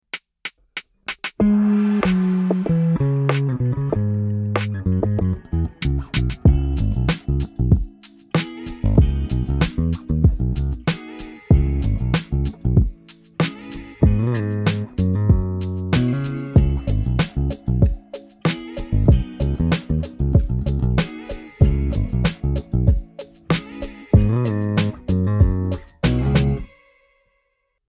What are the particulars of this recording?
A MusicMan StingRay straight into the DI02.